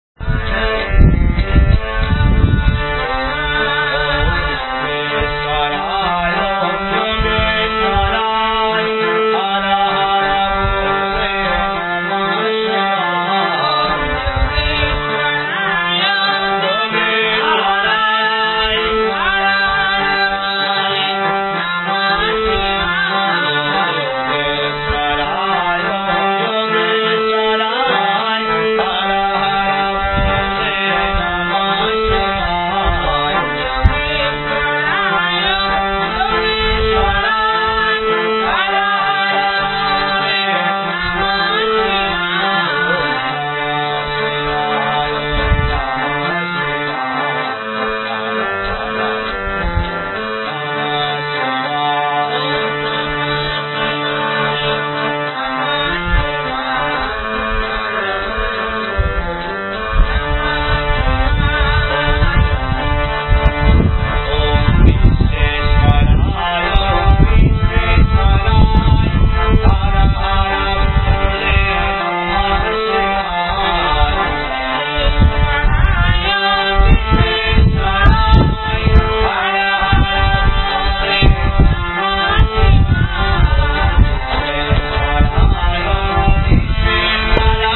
バラナシ ヒンドゥー寺院で歌われる宗教歌